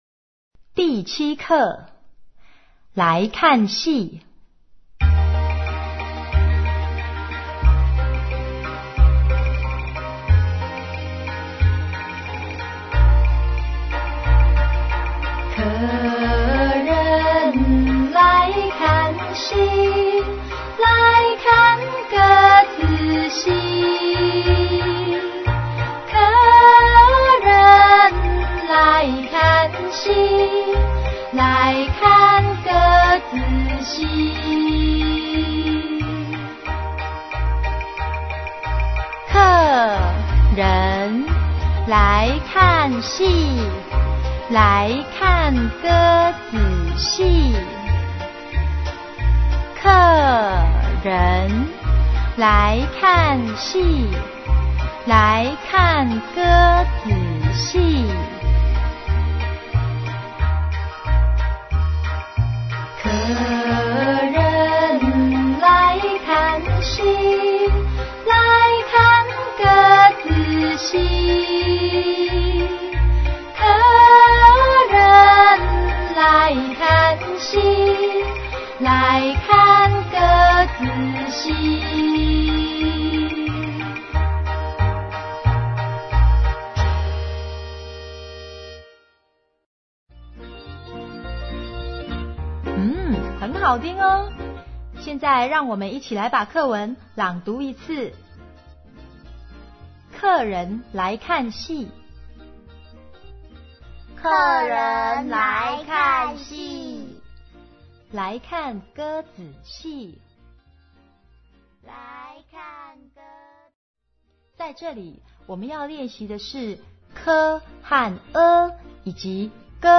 ◎CD裡附有好聽的「拼音兒歌」